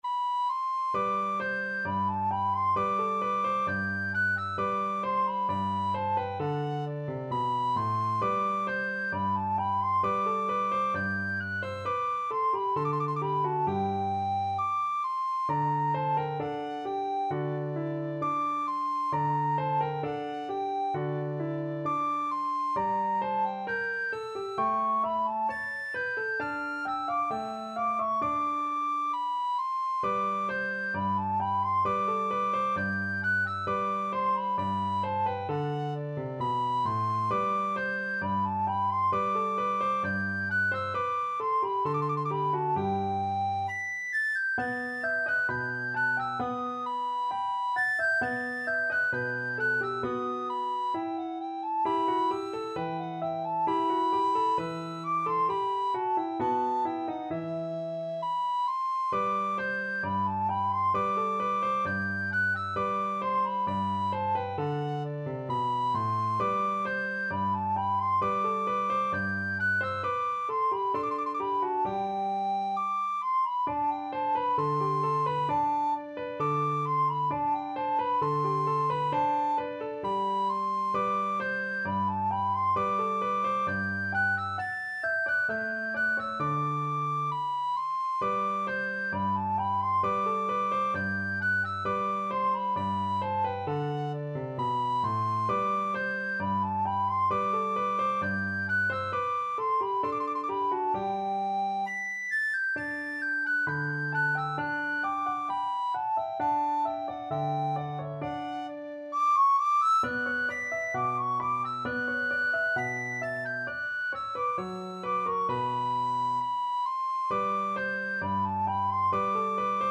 Classical Martini, Giovanni Battista Gavotte from Sonata No.12, B 4.I.12 Soprano (Descant) Recorder version
Recorder
G major (Sounding Pitch) (View more G major Music for Recorder )
2/2 (View more 2/2 Music)
~ = 100 Allegretto =c.66
Classical (View more Classical Recorder Music)
martini_gavotte12_REC.mp3